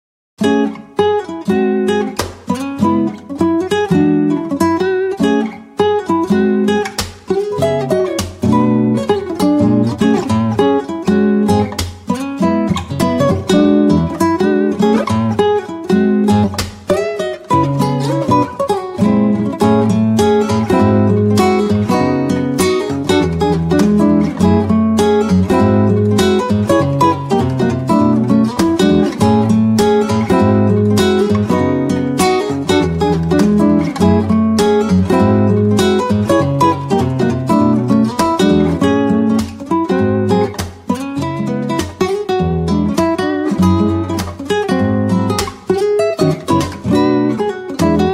featuring our virtual guitar